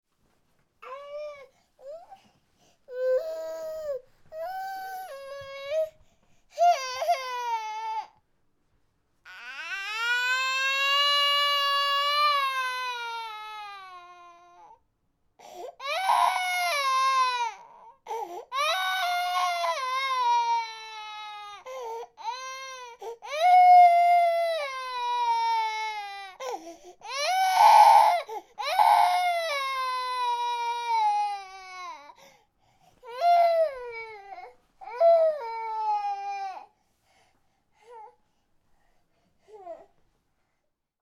ребенок звуки скачать, слушать онлайн ✔в хорошем качестве